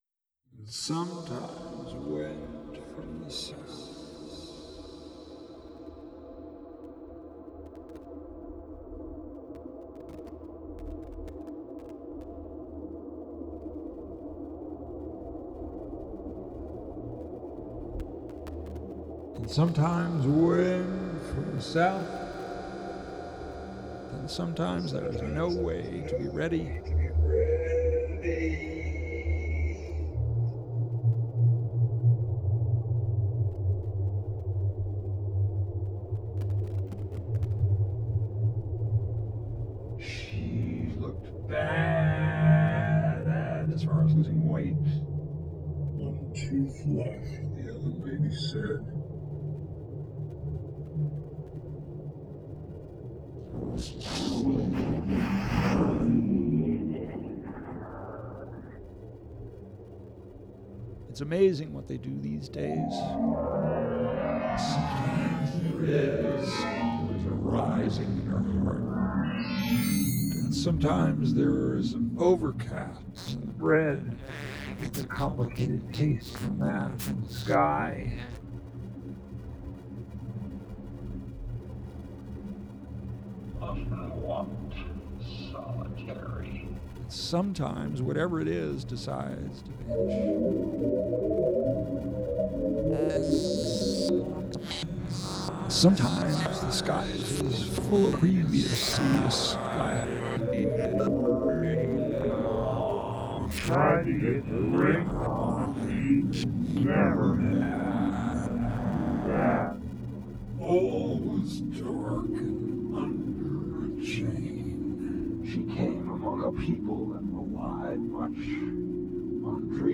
electroacoustic music compositions
This text-sound composition